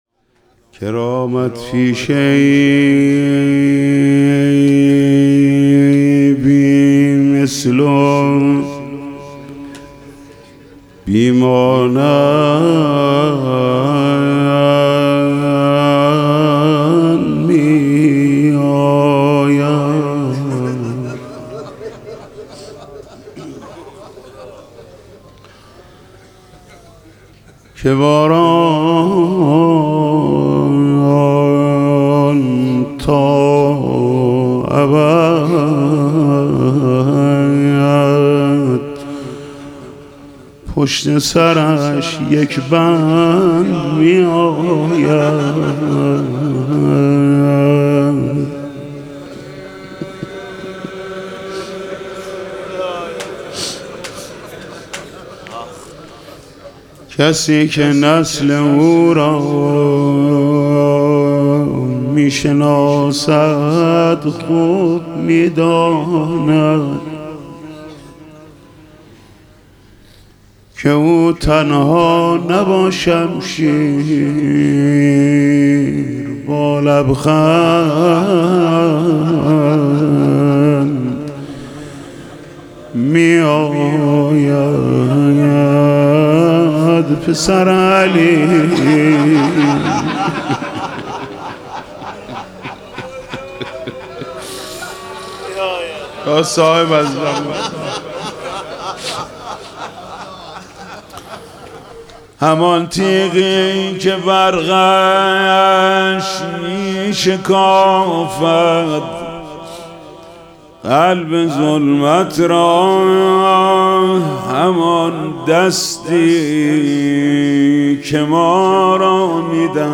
دانلود فایل صوتی مناجات خوانی مراسم شب شهادت امام صادق (ع) 1404 حاج محمود کریمی کرامت پیشه ای بی مثل و بی مانند - هیئت رایه العباس
شهادت امام صادق (ع) 1404